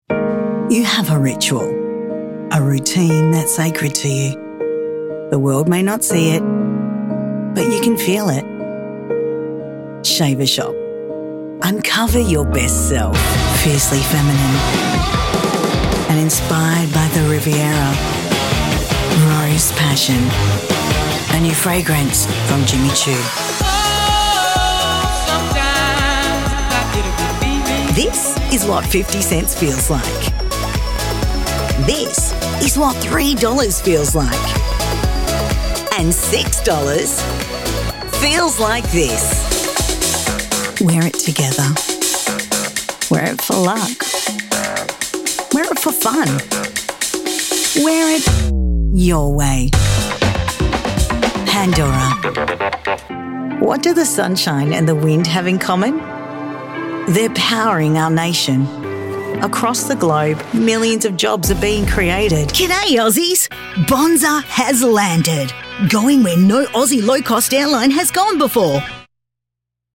English (Australian)
Commercial Demo